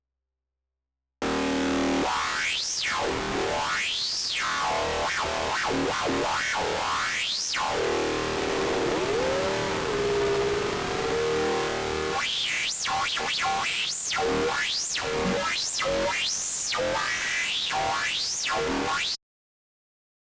Aggressive FM - Audionerdz Academy
Aggressive-FM.wav